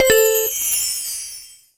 modern-checkpoint.mp3